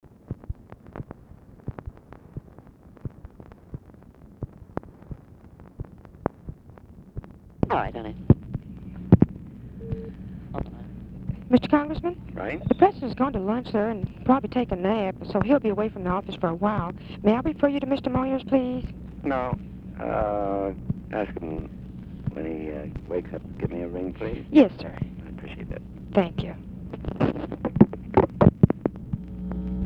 Conversation with ADAM CLAYTON POWELL, January 9, 1967
Secret White House Tapes